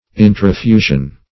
Search Result for " intrafusion" : The Collaborative International Dictionary of English v.0.48: Intrafusion \In`tra*fu"sion\, n. [Pref. intra- + L. fundere, fusum, to pour.]